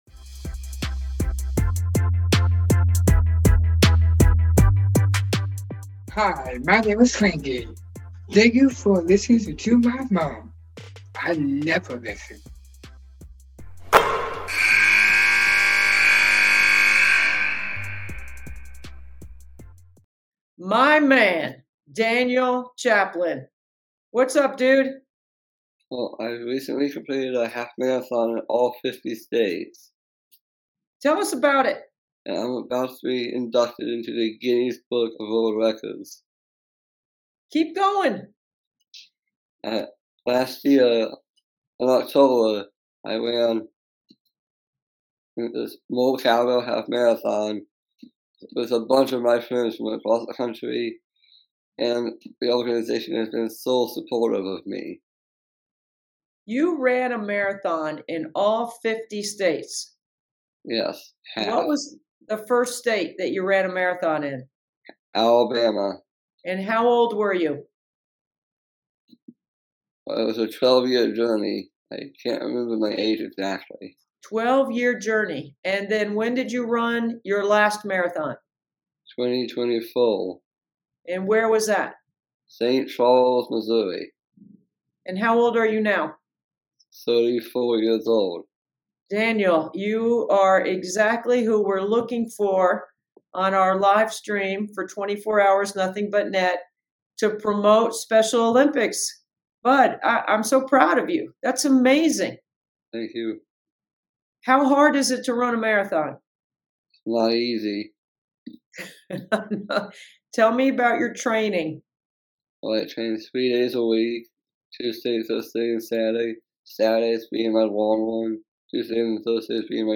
I hope you enjoy this replay of our interviews from this past May's 24 Hours of Nothing But Net with Debbie Antonelli.